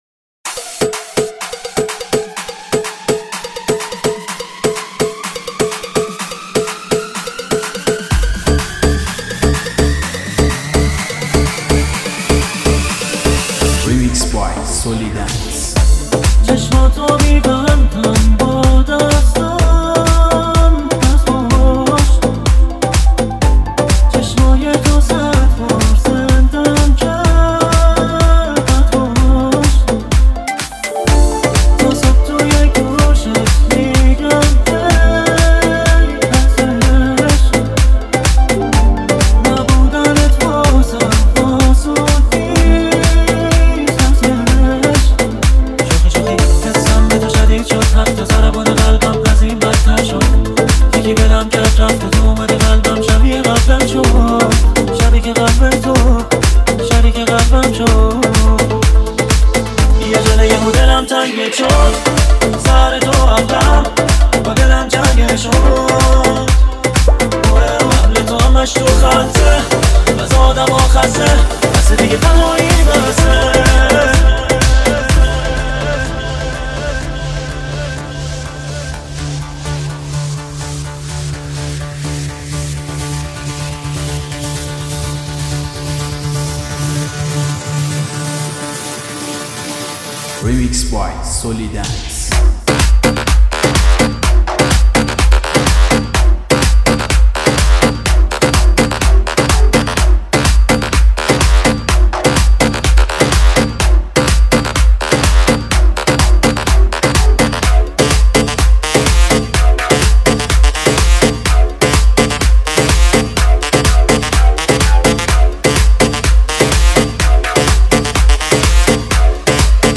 Deep House 2019